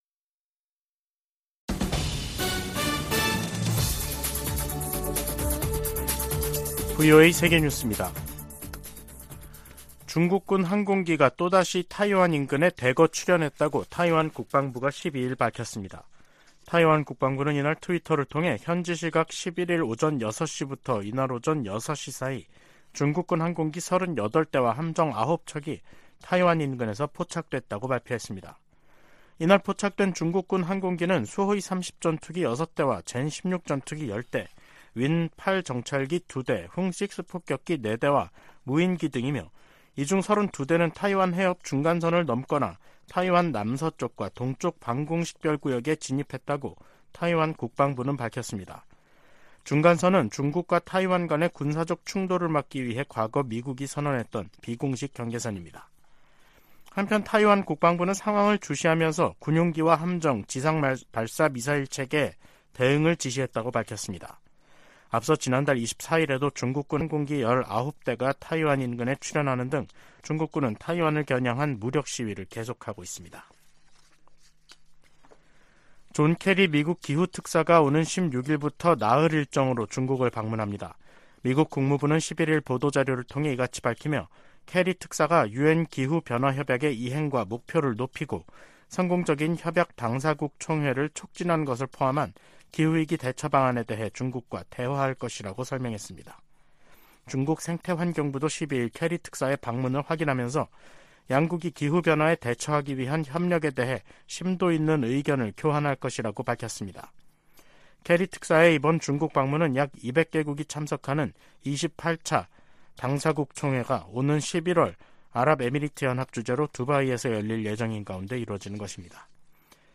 VOA 한국어 간판 뉴스 프로그램 '뉴스 투데이', 2023년 7월 12일 3부 방송입니다. 한국 정부가 북한 정권의 대륙간탄도미사일(ICBM) 발사를 규탄하며 불법 행위에는 대가가 따를 것이라고 경고했습니다. 미국과 한국, 일본의 북 핵 수석대표들도 북한의 ICBM 발사는 유엔 안보리 결의를 위반하는 심각한 도발로, 어떤 이유로도 정당화할 수 없다고 비판했습니다. 미 국무부는 미군 정찰기가 불법 비행했다는 북한 정권의 주장은 근거가 없다고 지적했습니다.